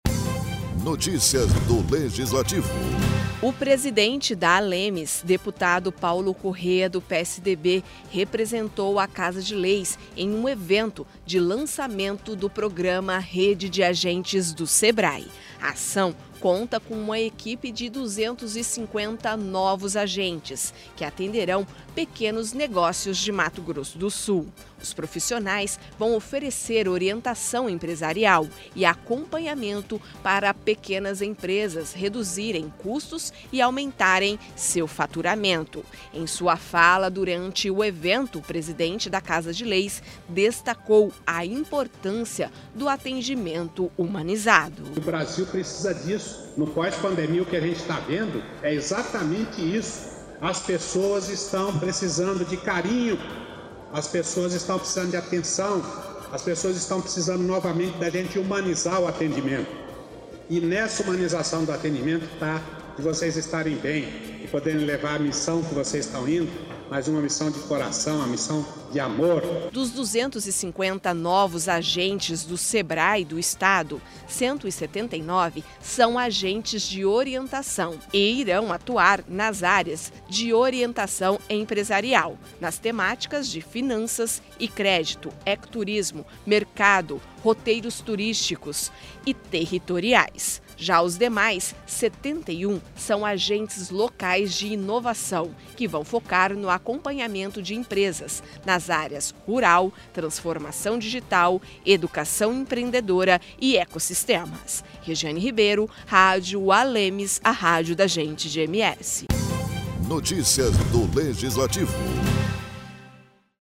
O presidente da Assembleia Legislativa de Mato Grosso do Sul (ALEMS), deputado Paulo Corrêa (PSDB), representou a Casa de Leis, no evento de lançamento do programa “Rede de Agentes do Sebrae”.